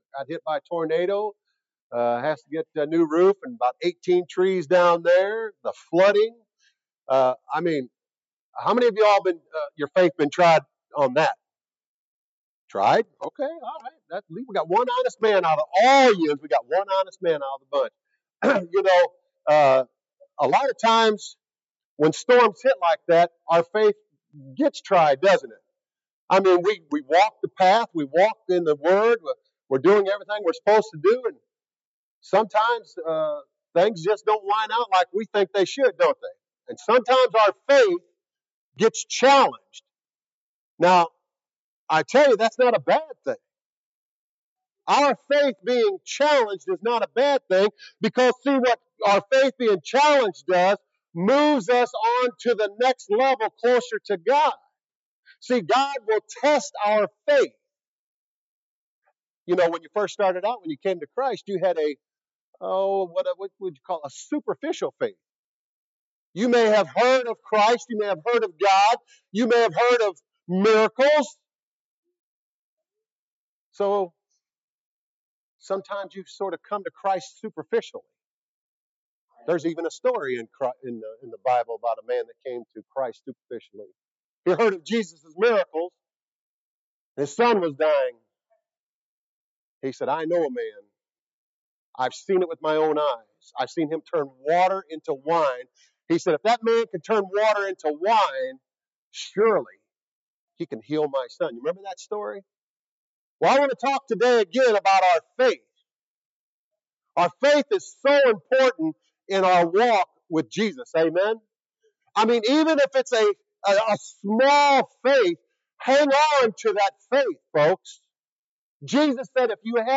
April-6-2025-Morning-Service.mp3